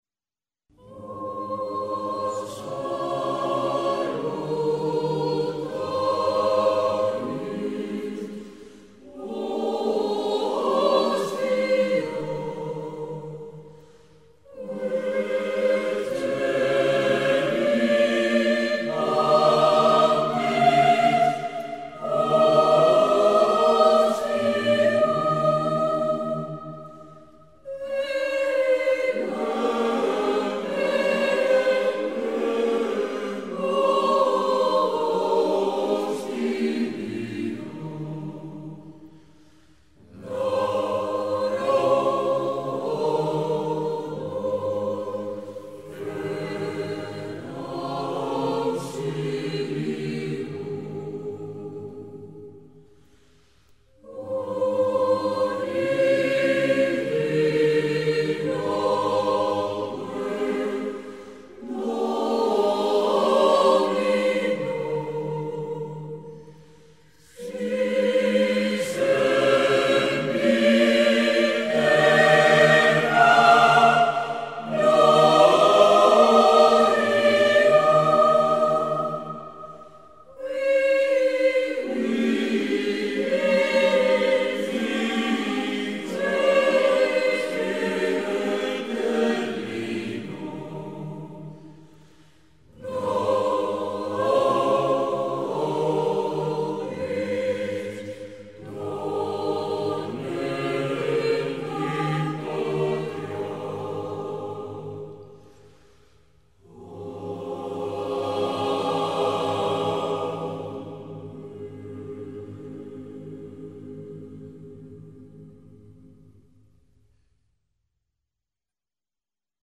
Clásica/Música Antigua